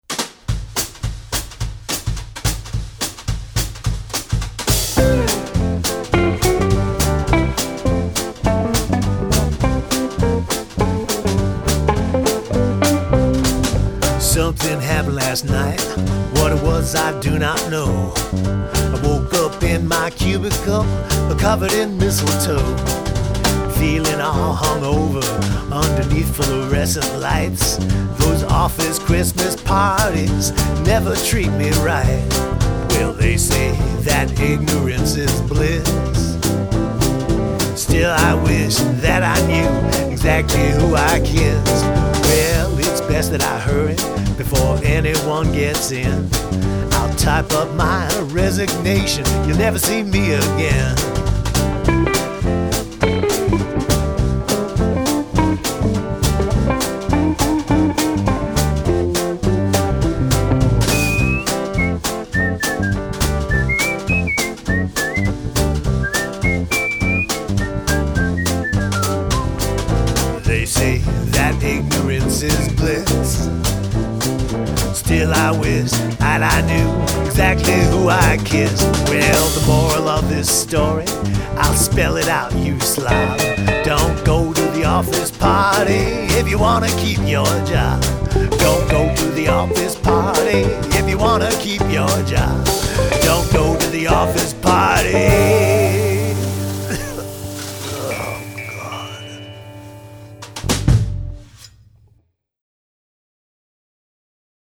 Bass
Drums
Vocals, Lead Guitar